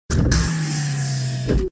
door close.ogg